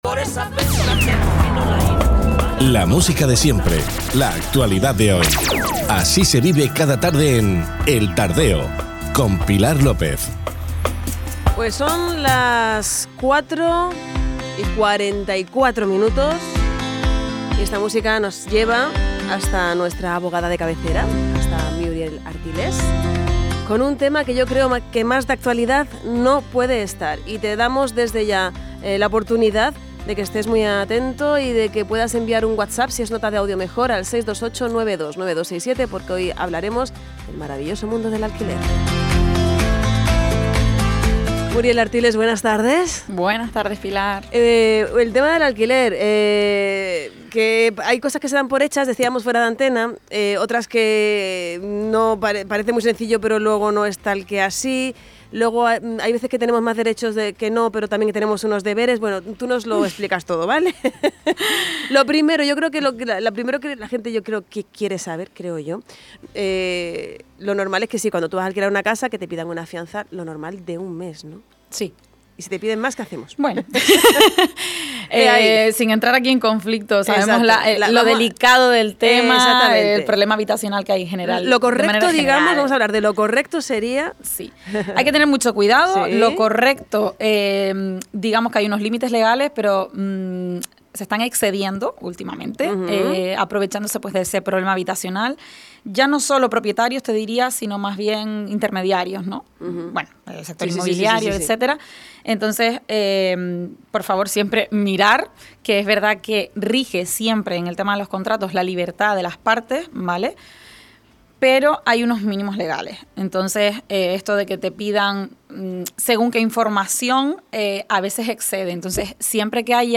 la sección de los lunes en El Tardeo de Radio Insular